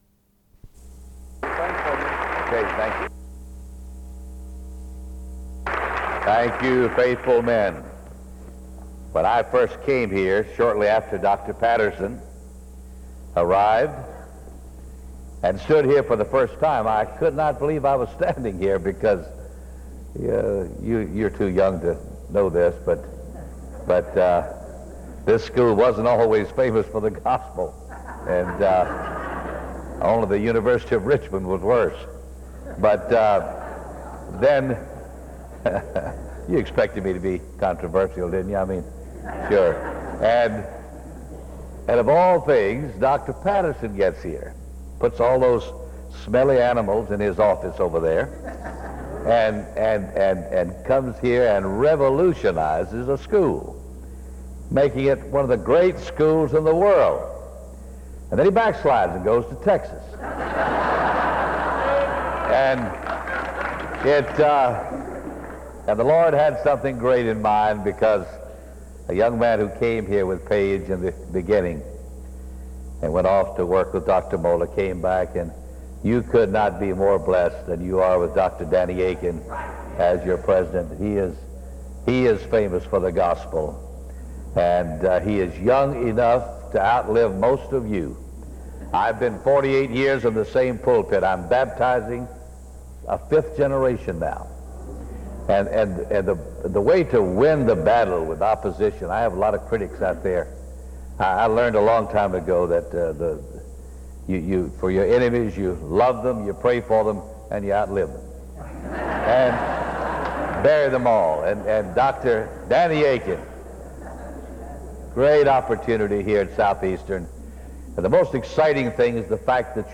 SEBTS Chapel - Jerry Falwell March 23, 2004
In Collection: SEBTS Chapel and Special Event Recordings - 2000s Miniaturansicht Titel Hochladedatum Sichtbarkeit Aktionen SEBTS_Chapel_Jerry_Falwell_2004-03-23.wav 2026-02-12 Herunterladen